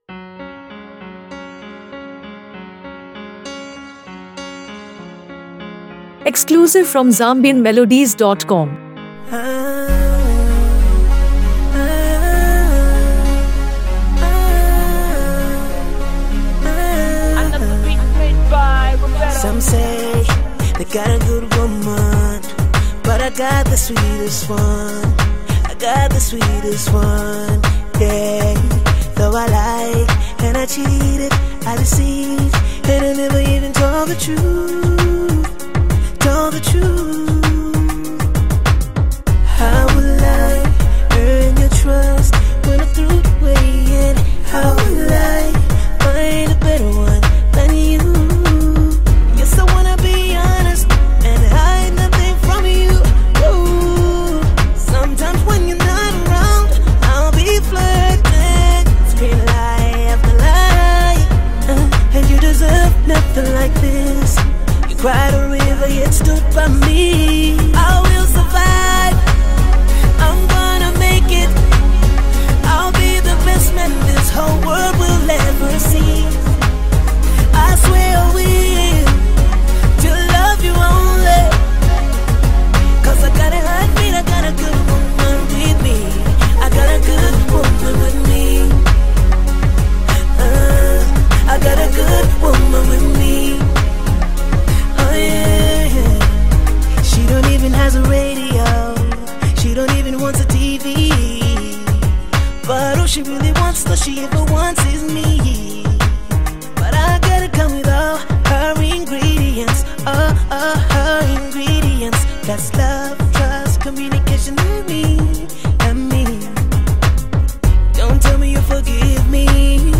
smooth vocals, captivating melodies, and relatable lyrics